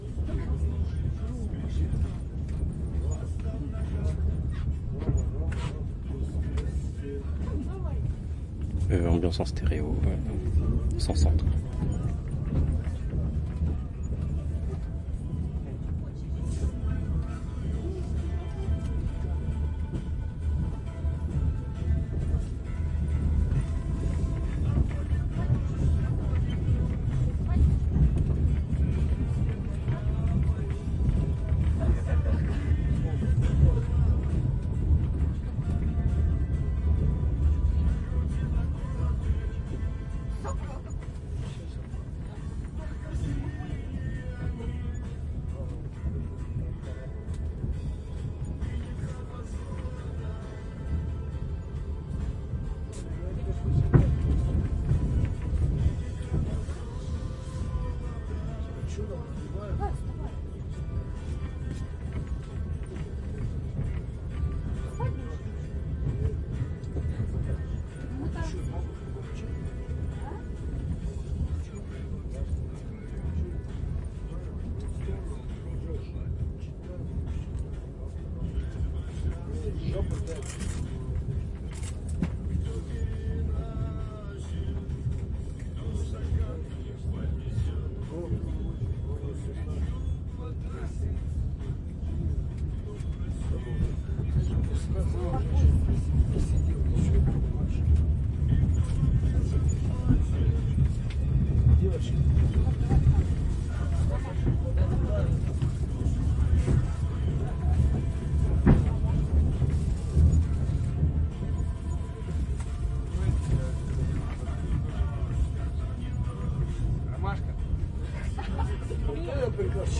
俄罗斯横贯西伯利亚的火车 " 俄罗斯的火车声音广播
描述：Trans西伯利亚火车。三等车，人们说话。无线电。 Zaxcom NomadSchoeps ortf
声道立体声